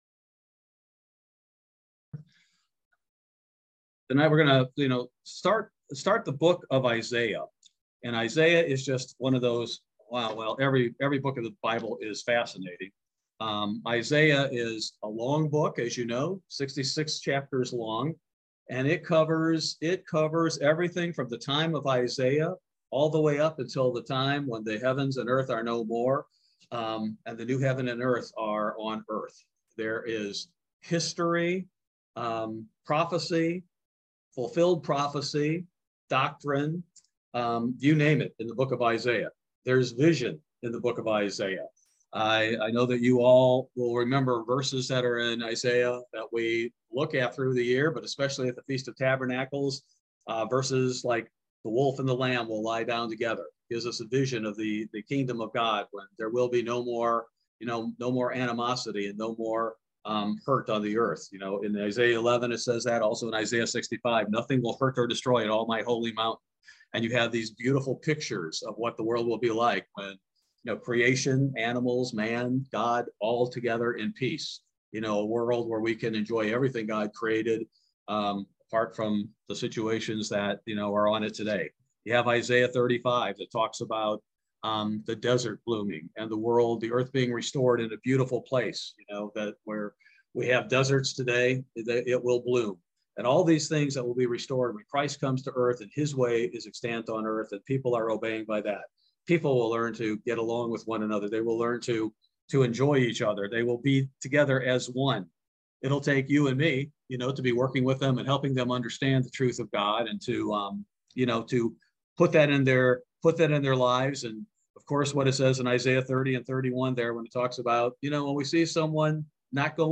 Bible Study: June 15, 2022